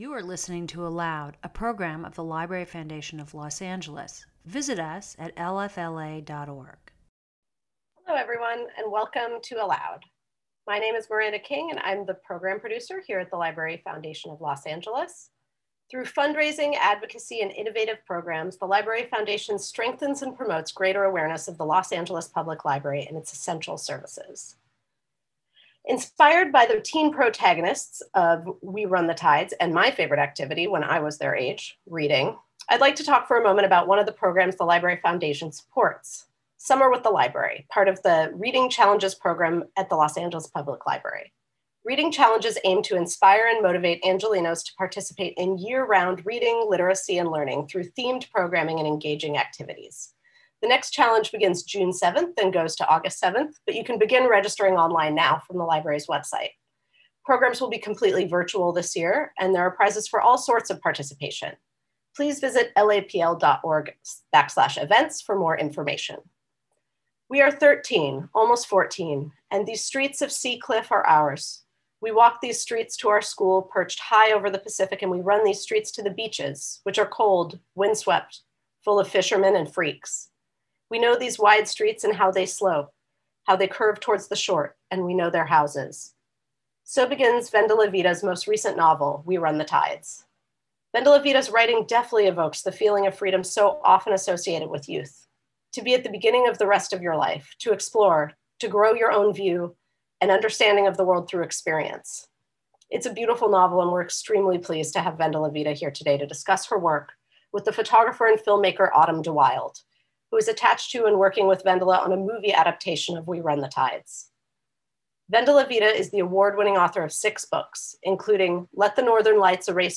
Join us for a conversation with Vida and Autumn de Wilde, as they discuss this enigmatic coming-of-age story in all its beauty and confusion.